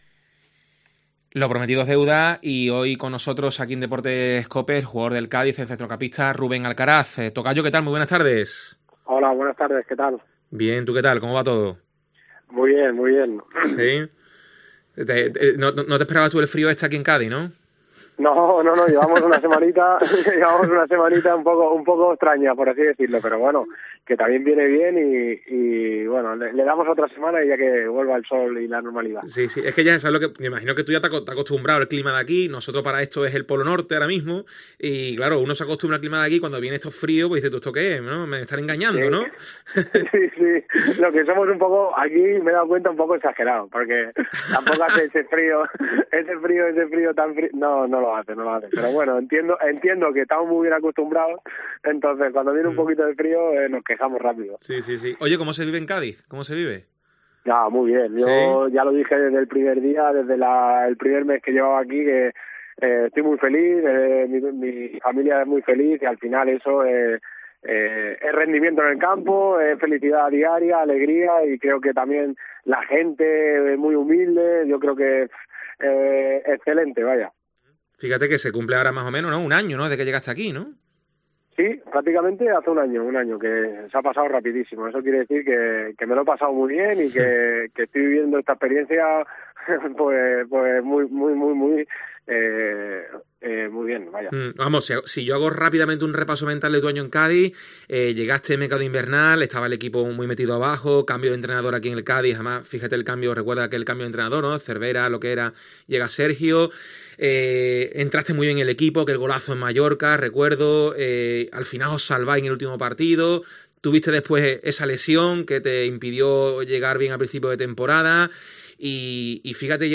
ESCUCHA LA ENTREVISTA A RUBÉN ALCARAZ EN COPE CÁDIZ